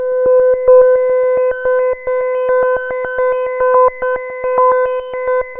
JUP 8 B5 11.wav